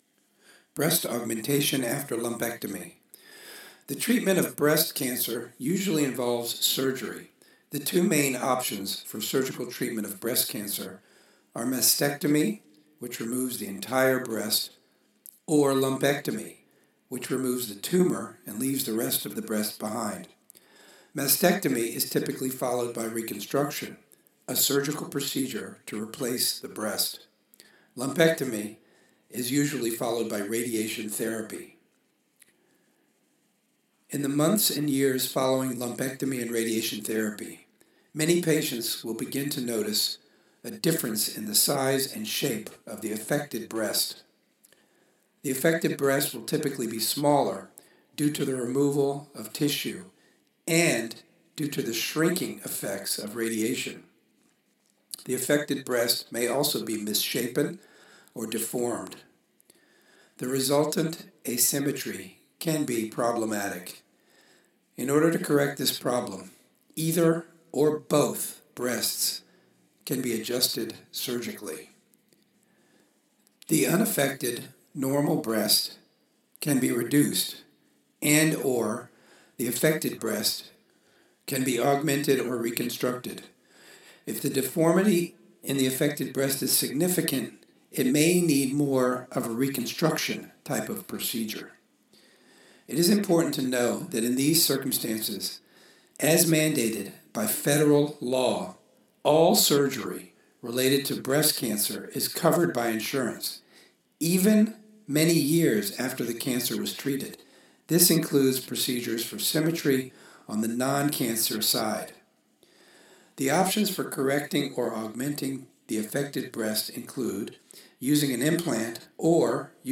Article Narration